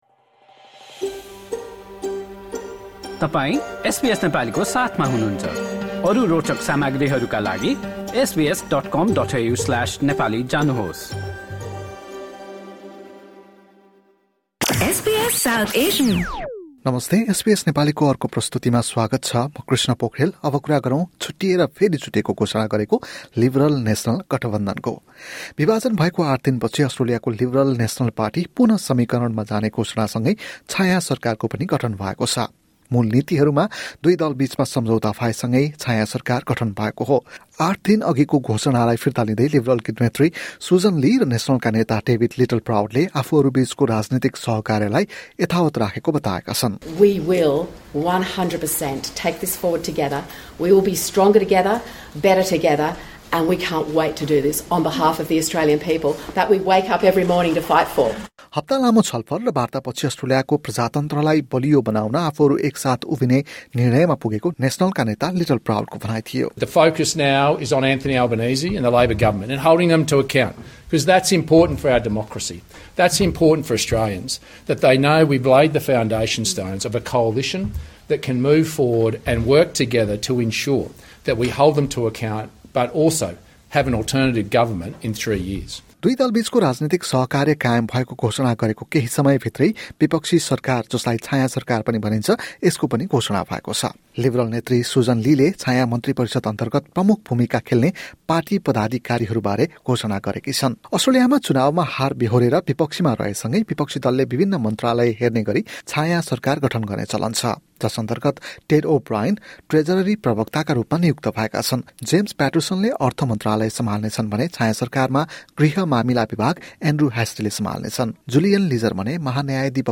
मूल नीतिहरूमा दुई दलबिचमा सम्झौता भएसँगै, छायाँ सरकार पनि गठन भएको छ। एक रिपोर्ट।